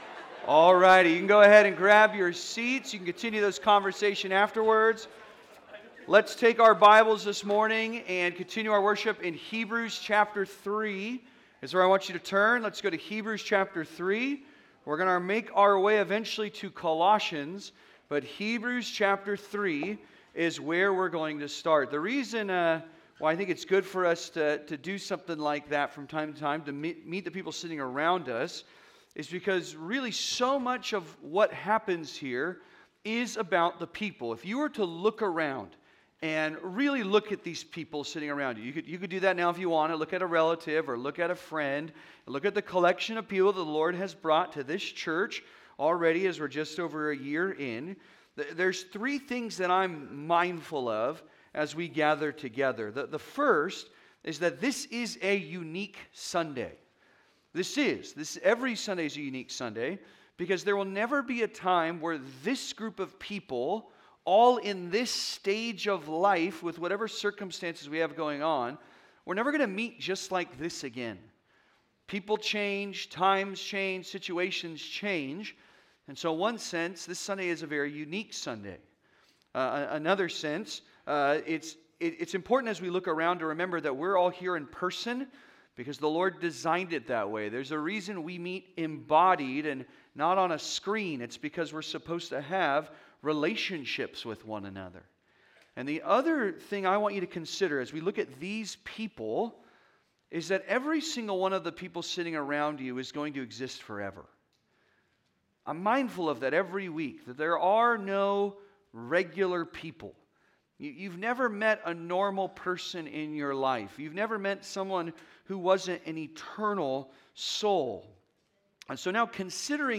The Right Kind of Church Growth (Sermon) - Compass Bible Church Long Beach